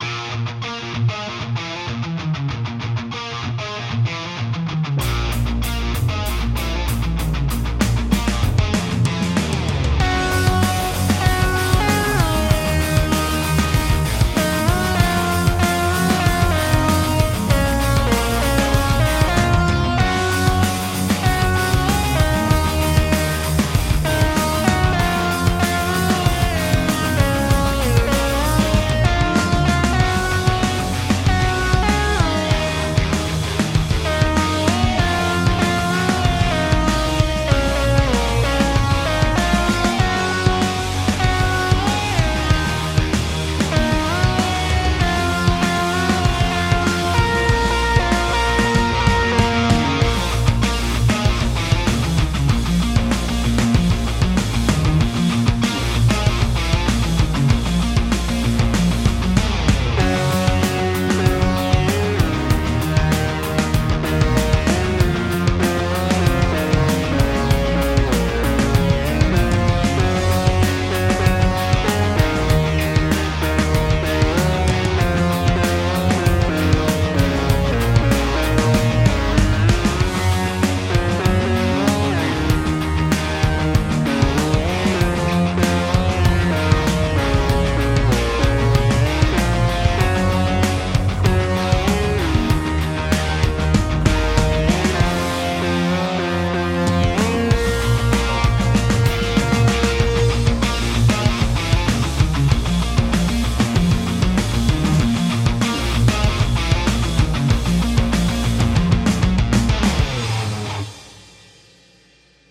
standard combat theme